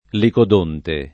[ likod 1 nte ]